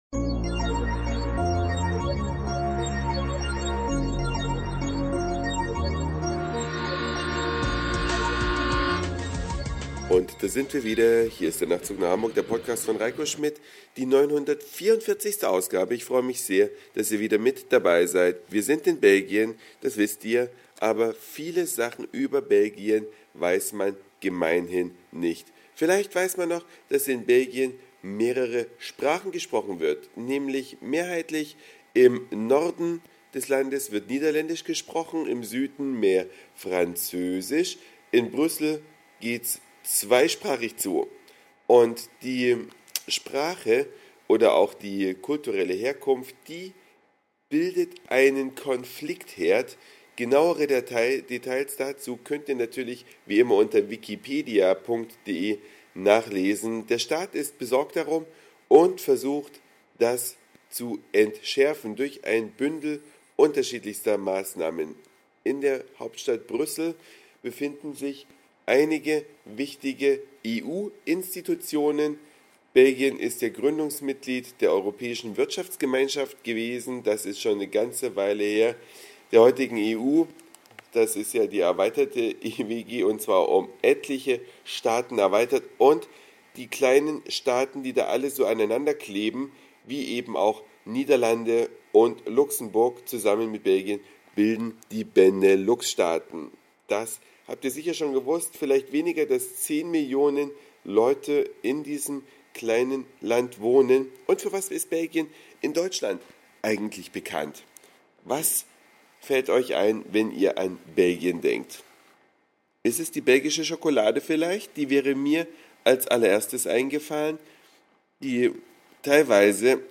Eine Reise durch die Vielfalt aus Satire, Informationen, Soundseeing und Audioblog.